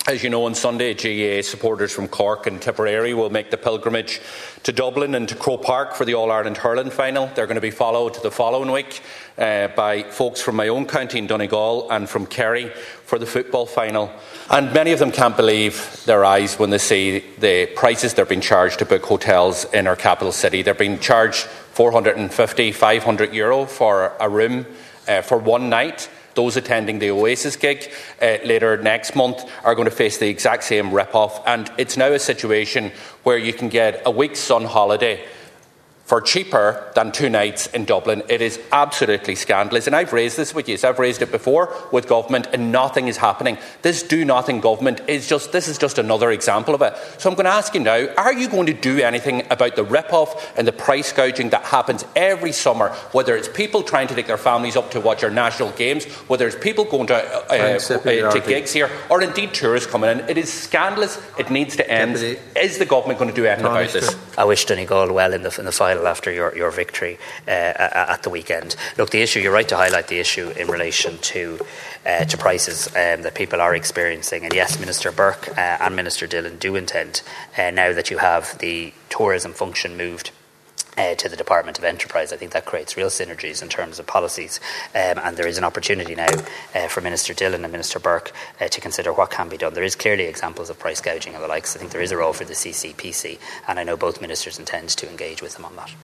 Dublin hotel prices under the microscope in discussion between Doherty and Harris